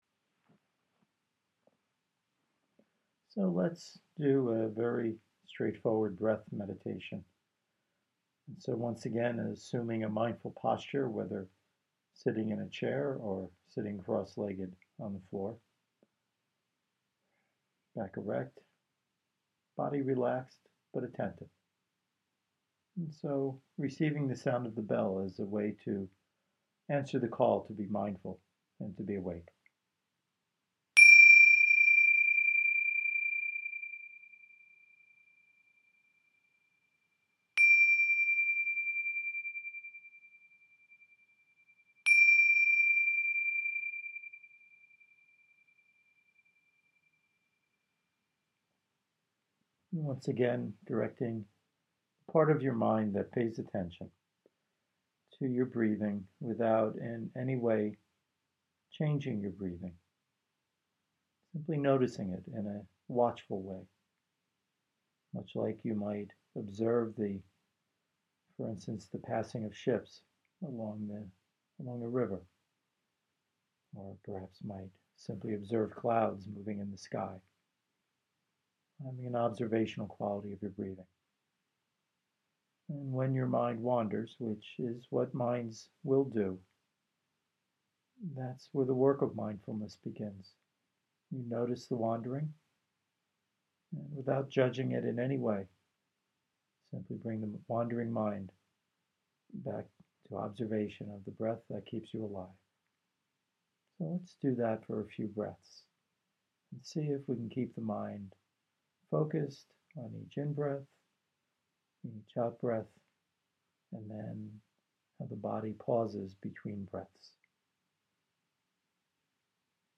Here is a group of guided meditations that might be helpful for you.
breath-meditation-acceptance-11-minutes.mp3